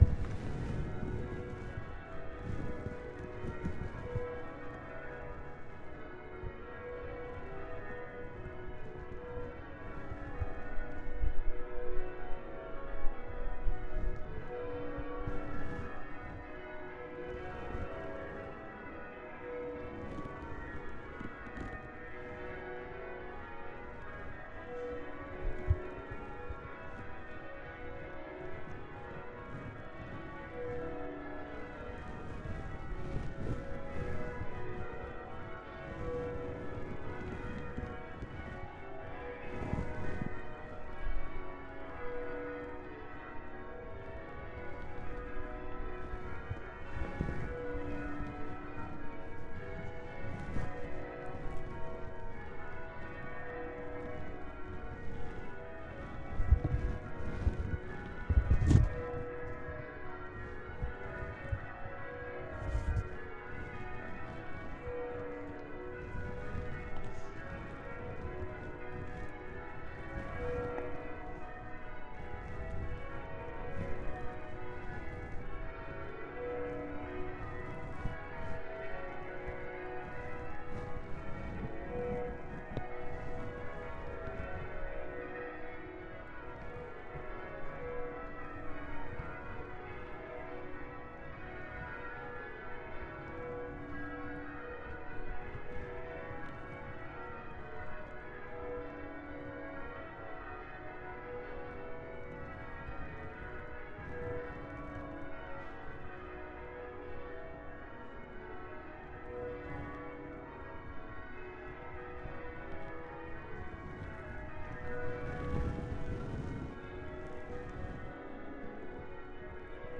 bellringing practice at Worcester Cathedral 1. Sorry about huge coat russlage.